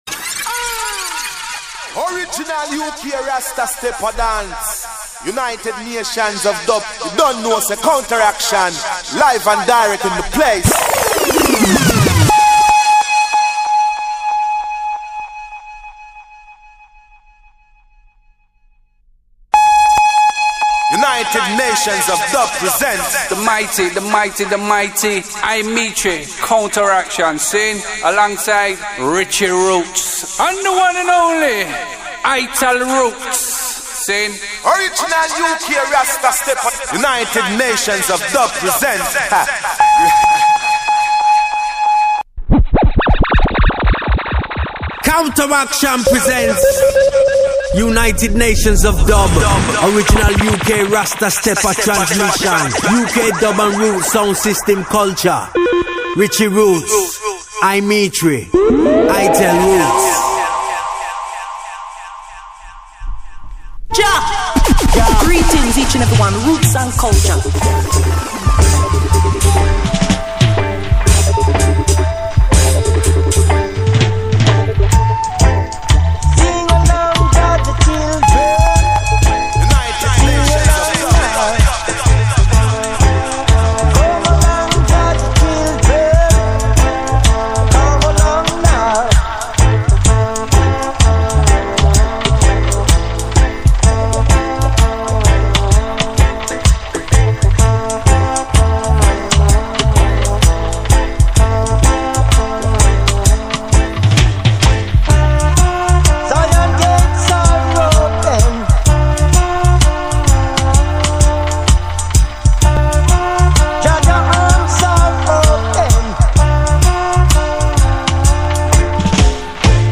Strictly vinyl selection